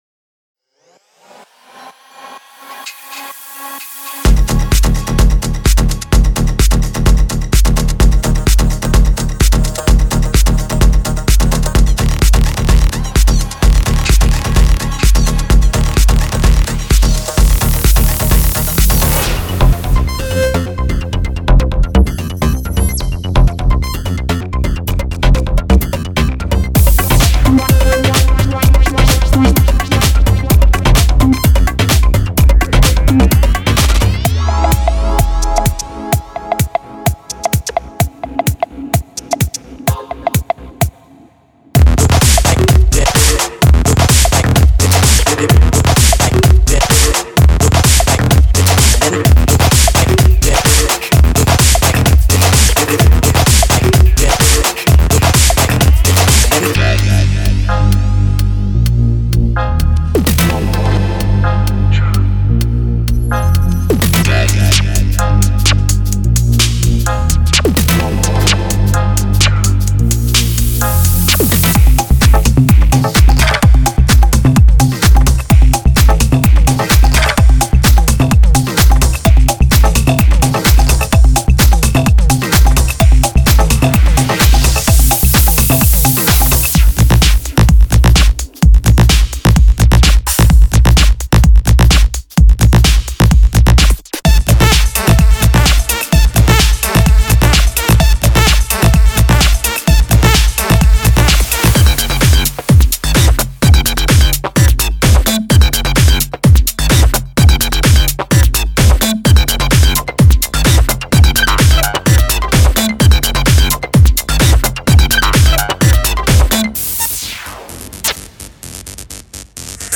MP3 DEMO